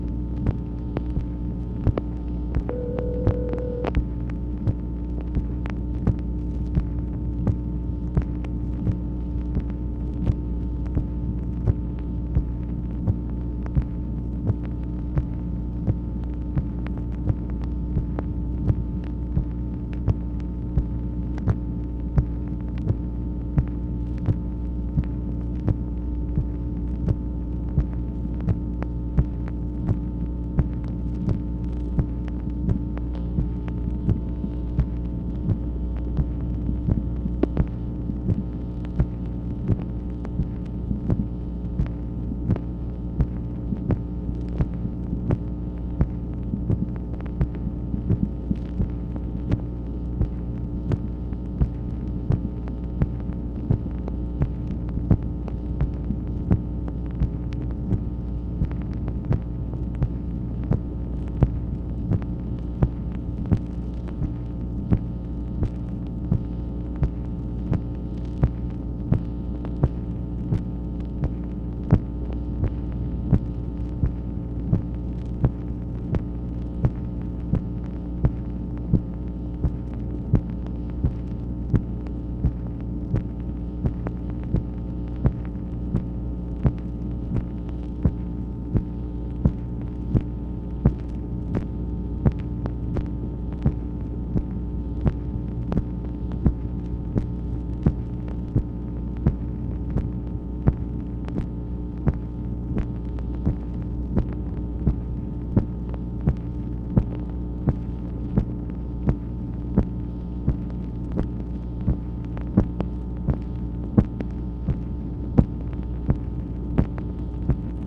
MACHINE NOISE, September 26, 1966
Secret White House Tapes | Lyndon B. Johnson Presidency